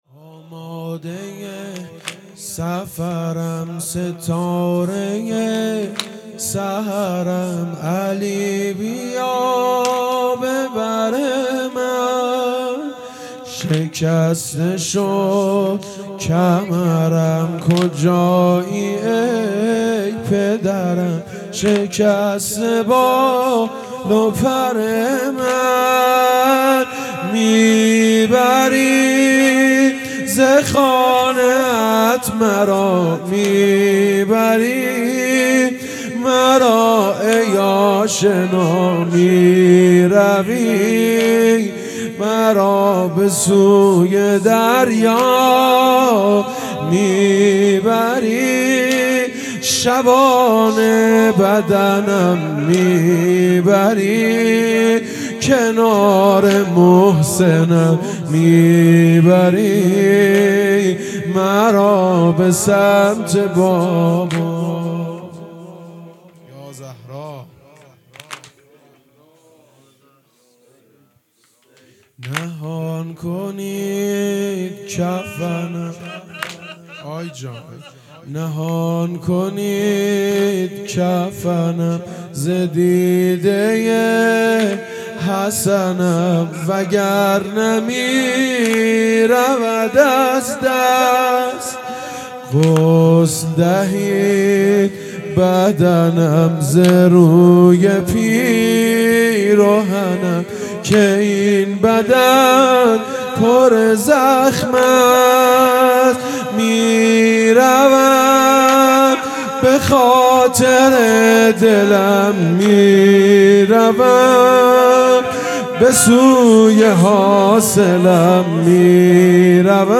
سنگین | آمادهی سفرم ستارهی سحرم
شام‌شهادت‌حضرت‌زهرا(س)
فاطمیه